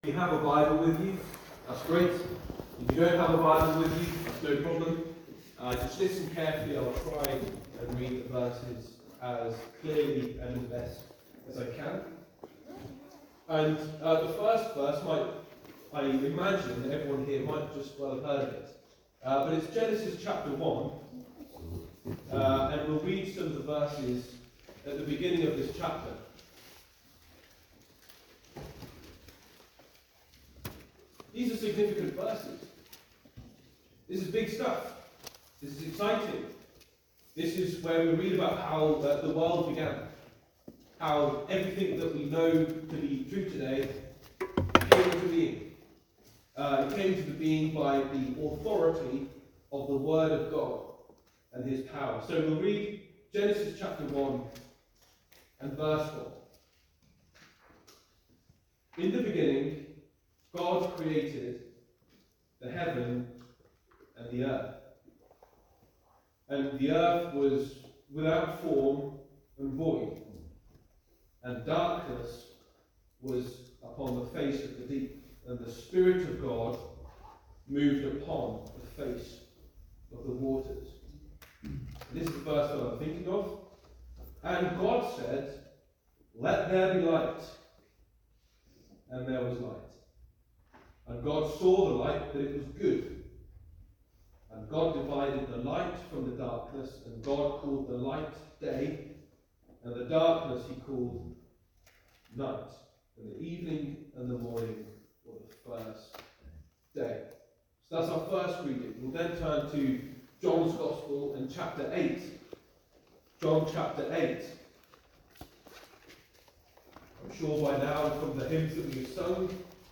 In this gospel message, we examine a couple of the significant statements that describes who God is.